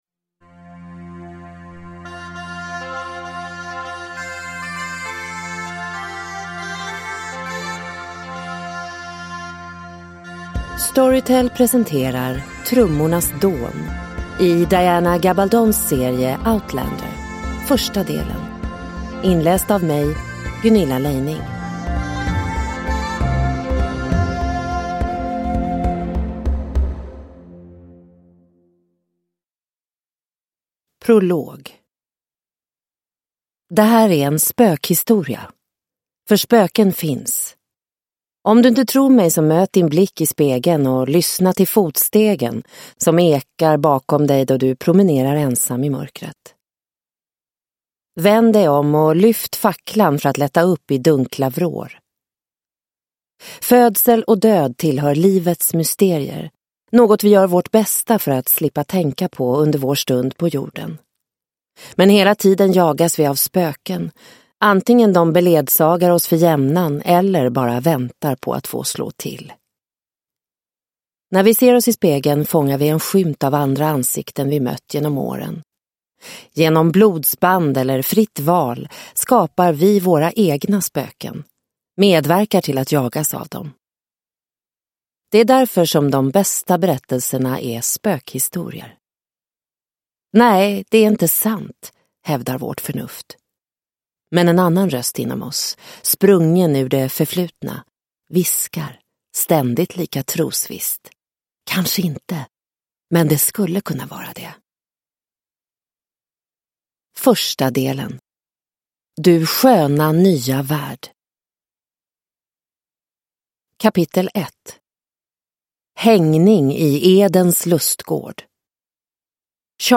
Trummornas dån - del 1 (ljudbok) av Diana Gabaldon